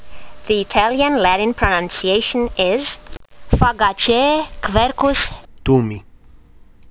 Listen to the Latin Print a QR link to this factsheet symbol: QUTO2